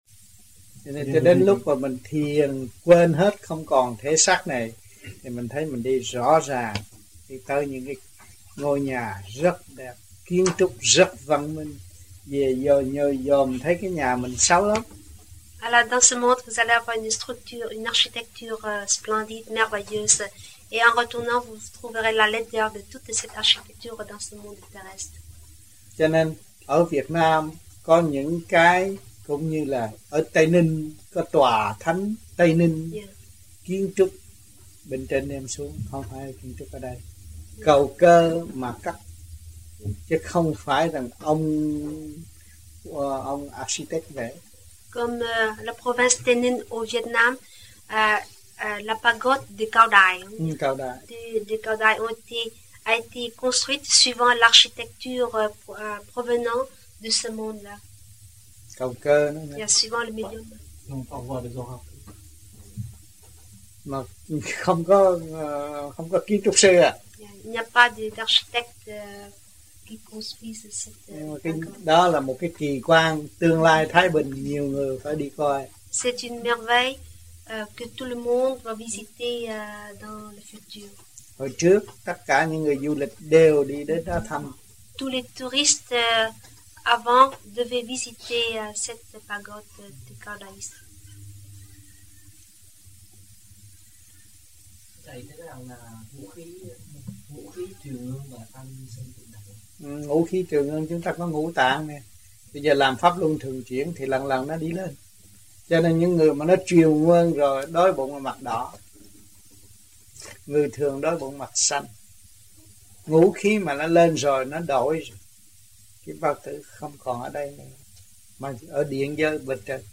1982 Khóa Học Toulouse